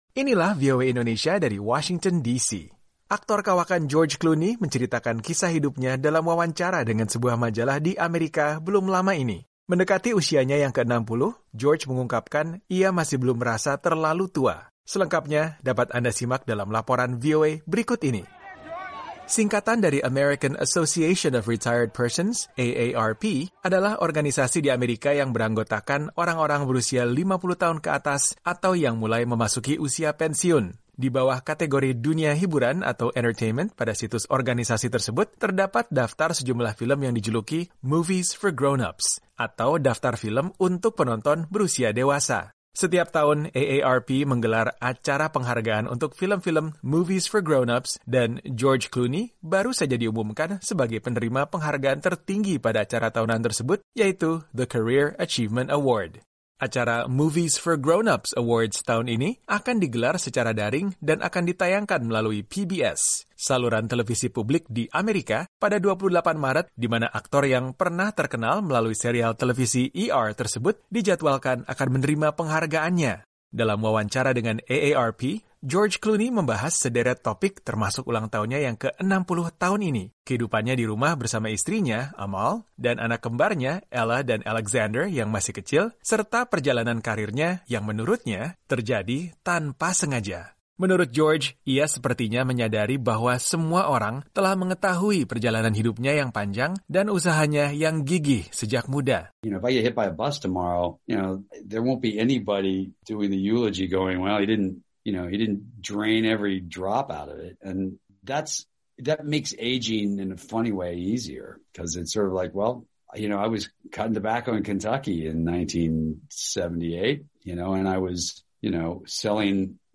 Selengkapnya dapat Anda simak dalam laporan VOA berikut ini.